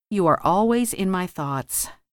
Sua pronúncia se dá com o “th” com a língua entre os dentes soltando o ar devagar, como em through, “gh” mudo e “t” no final: /θɔːt/